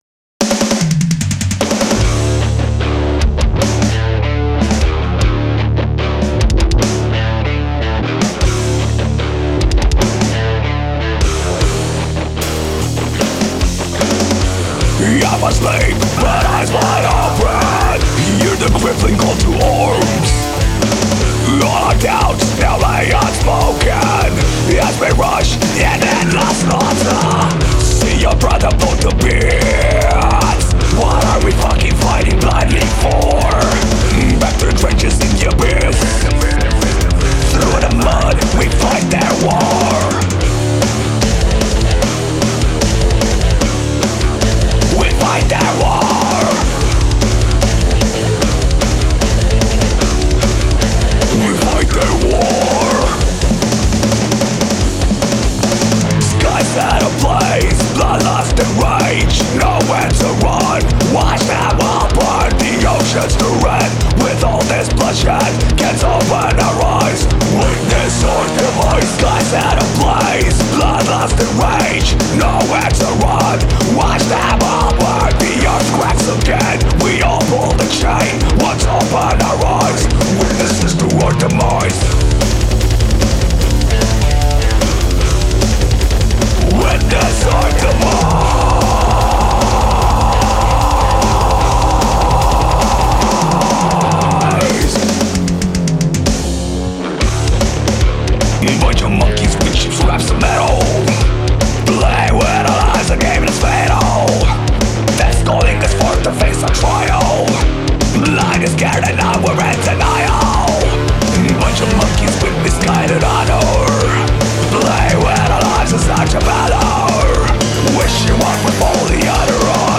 We're a metal band from Bucharest